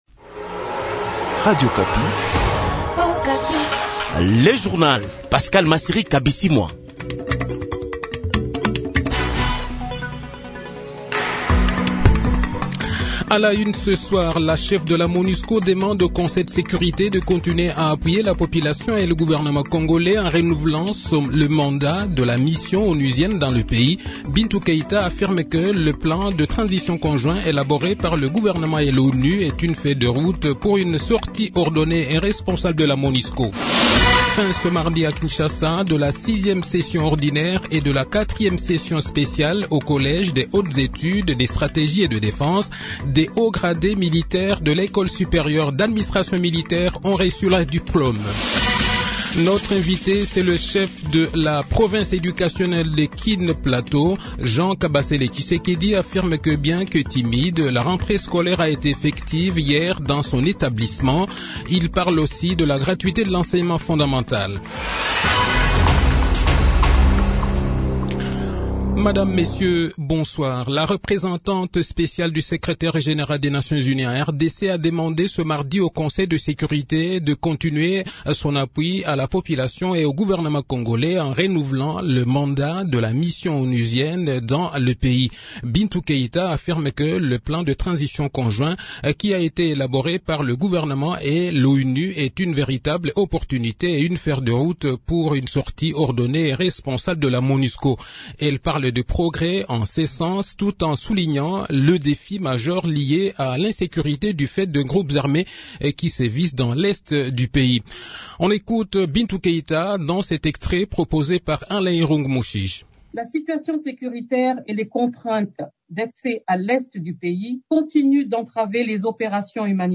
Journal Soir
Le journal de 18 h h, 5 Octobre 2021